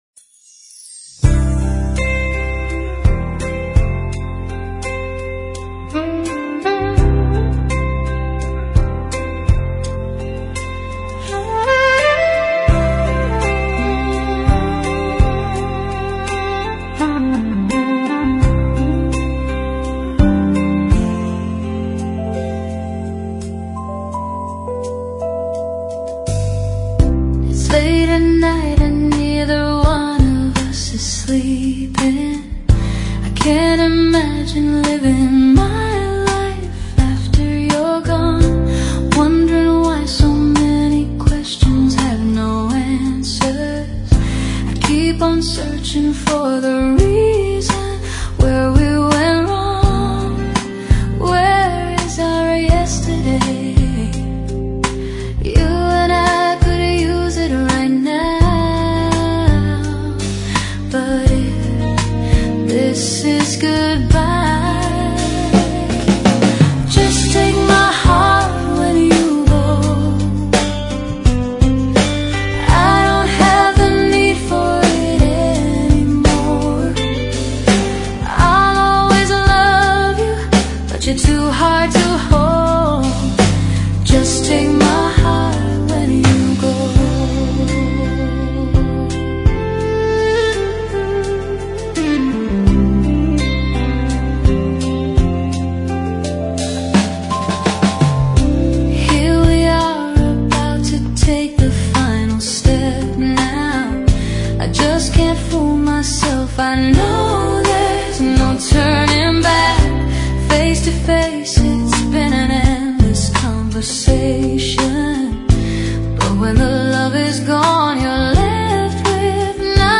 Chill Jazz